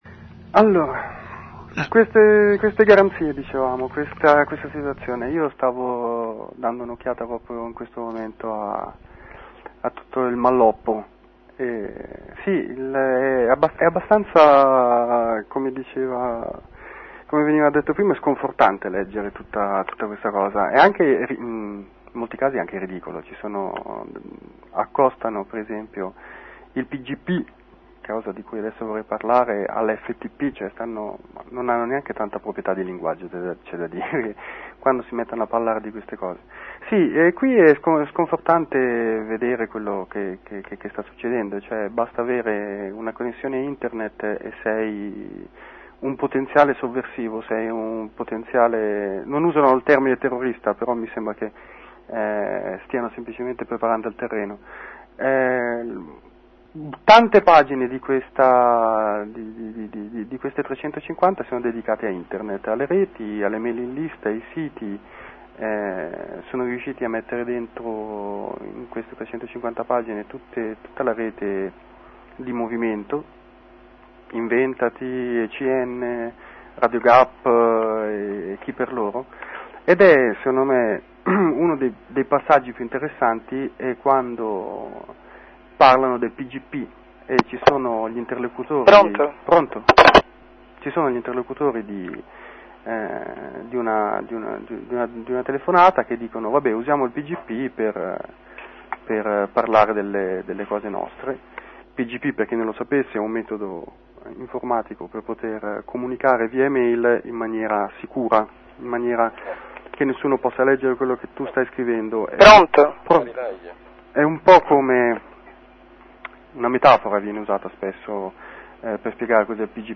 Cosa sia, quali le sue funzioni e le sue caratteristiche, ce lo spiega un suo utilizzatore e conoscitore.